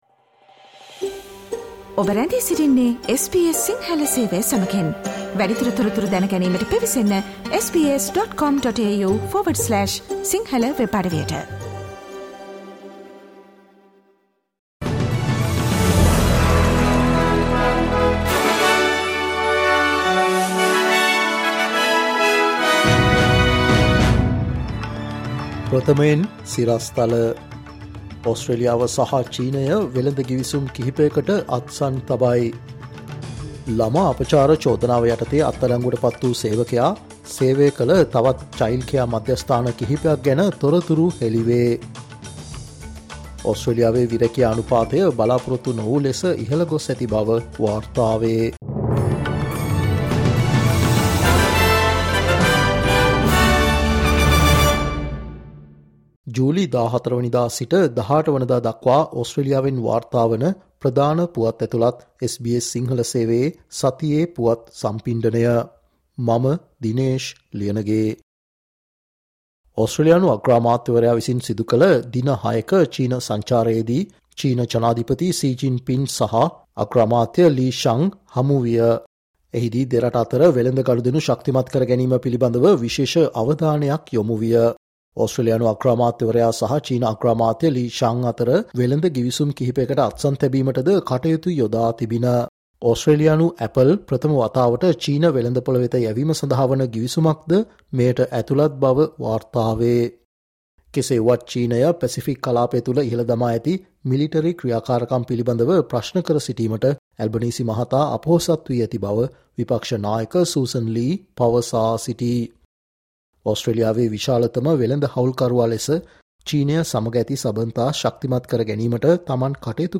ජූලි 14 වනදා සිට ජූලි 18 වනදා දක්වා වන මේ සතියේ ඕස්ට්‍රේලියාවෙන් වාර්තාවන පුවත් ඇතුලත් සතියේ පුවත් ප්‍රකාශයට සවන් දෙන්න